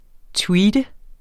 Udtale [ ˈtwiːdə ]